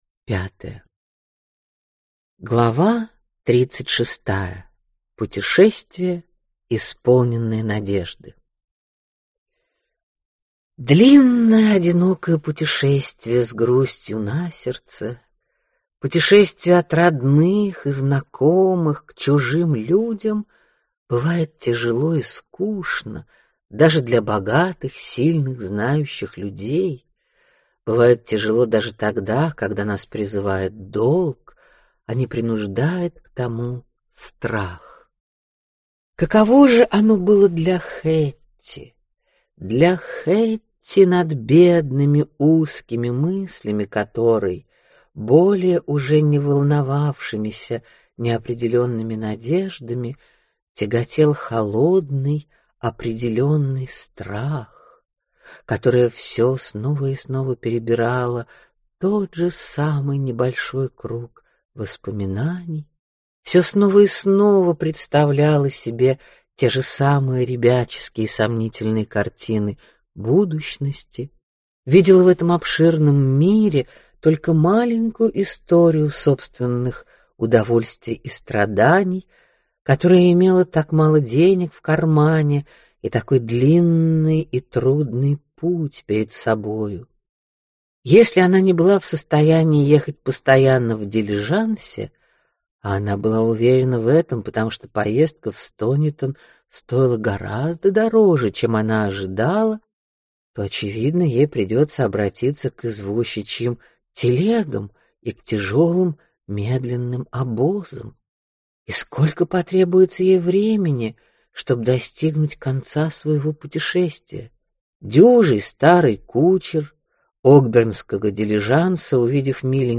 Аудиокнига Адам Бид. Часть 3 | Библиотека аудиокниг